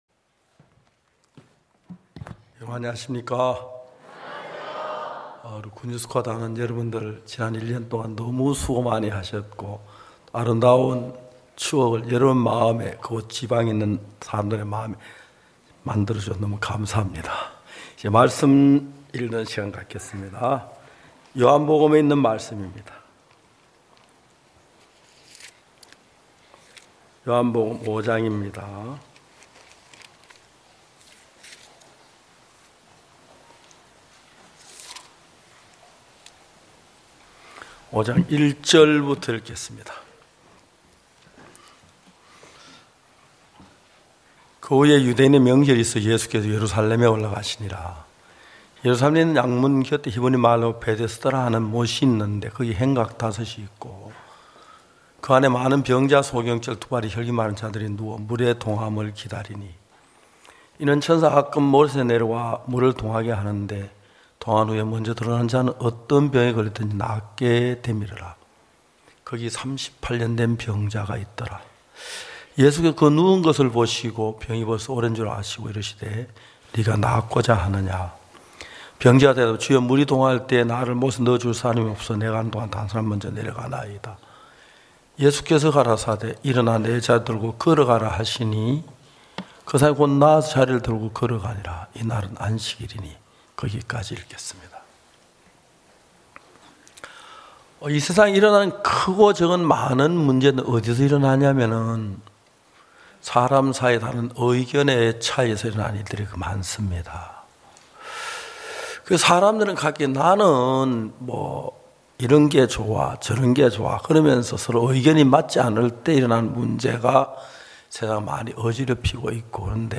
전국 각 지역의 성도들이 모여 함께 말씀을 듣고 교제를 나누는 연합예배.